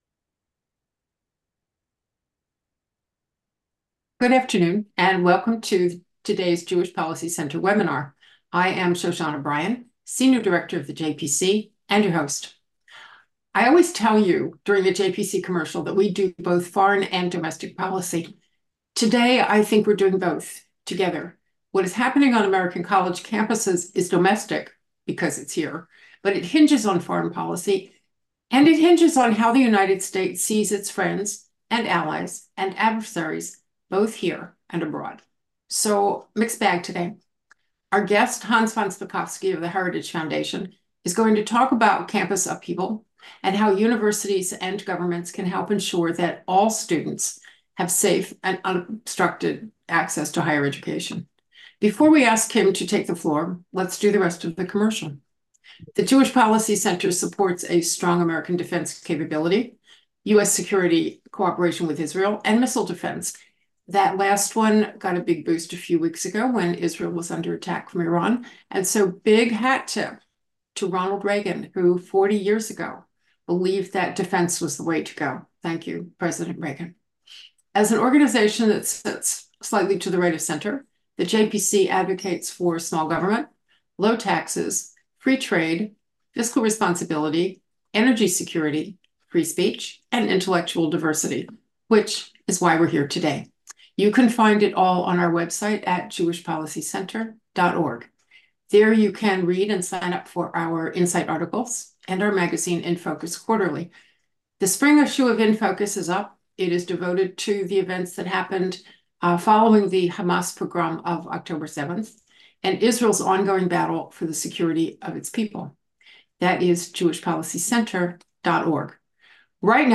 upcoming-webinar-destructive-discord-and-college-campuses.m4a